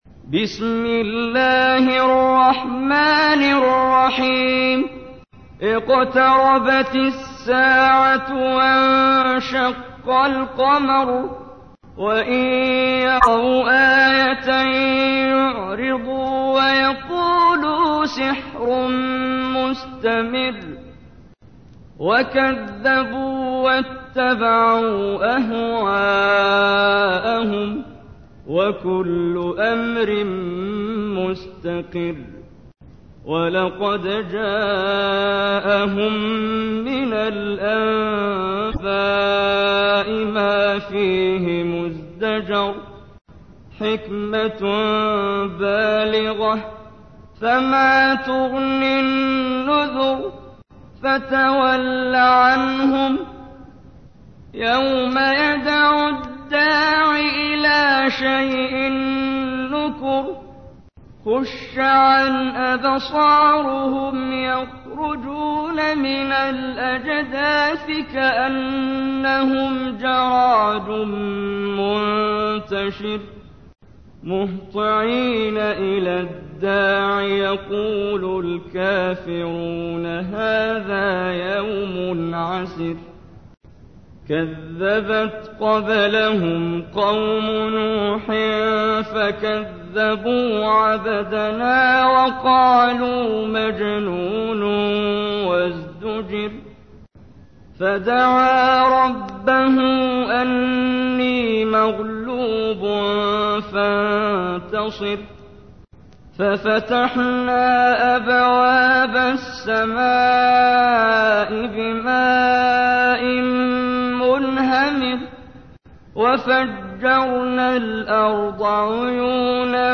تحميل : 54. سورة القمر / القارئ محمد جبريل / القرآن الكريم / موقع يا حسين